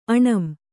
♪ aṇam